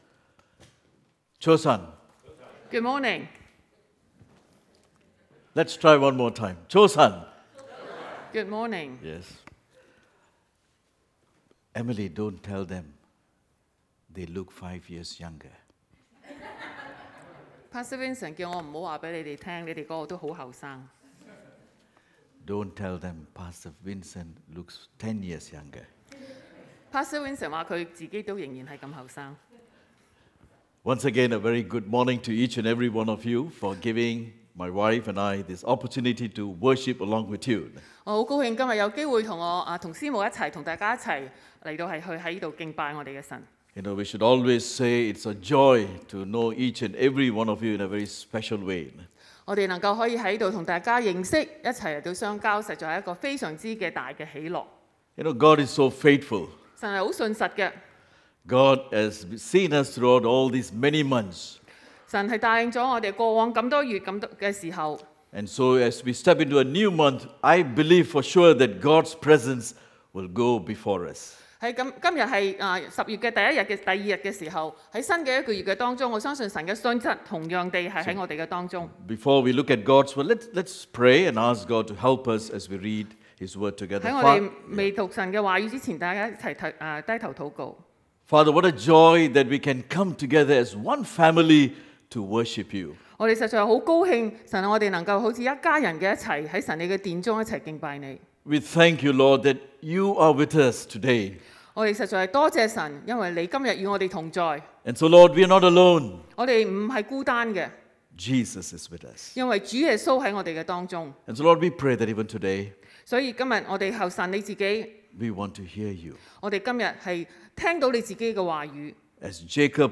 Sermons | New Covenant Alliance Church (NCAC) 基約宣道會 - Part 14